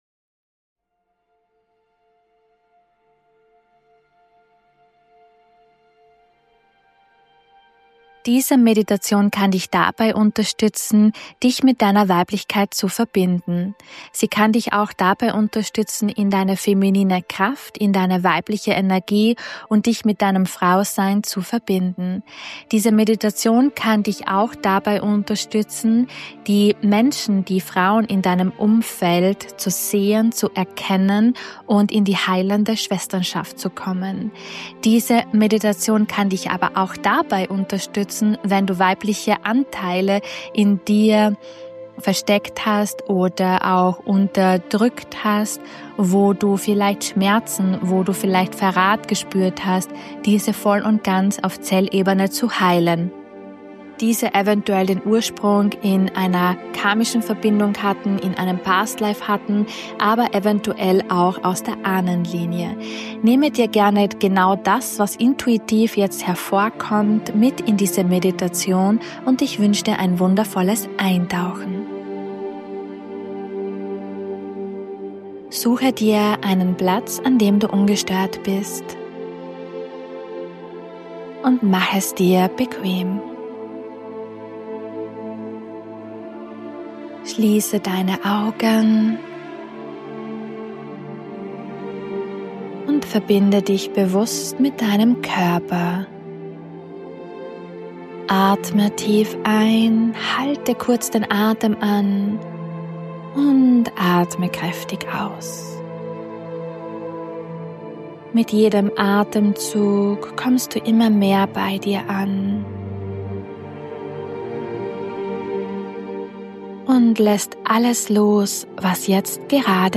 Diese Meditation kann dich dabei unterstützen in deine Weiblichkeit zu finden & dein Frausein anzunehmen.